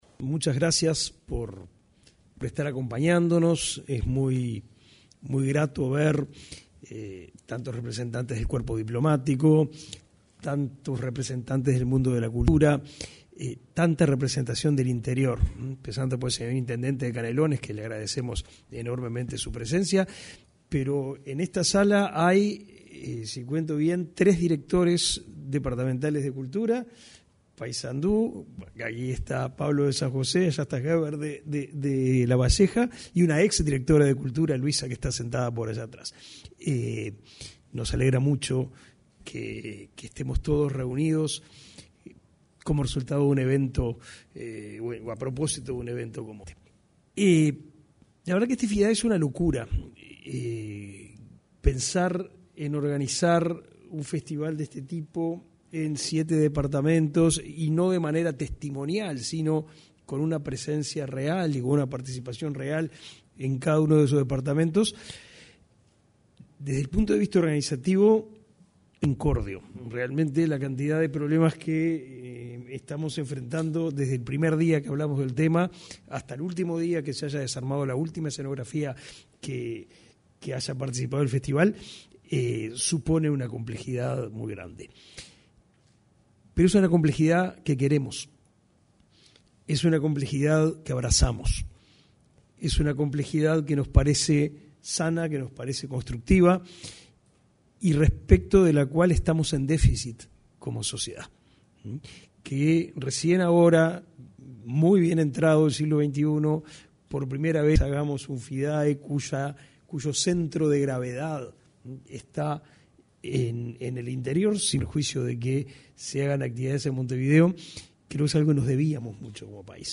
Palabras del ministro de Educación y Cultura, Pablo da Silveira
El Ministerio de Educación y Cultura (MEC) realizó, este 12 de junio, el lanzamiento del Festival Internacional de Artes Escénicas.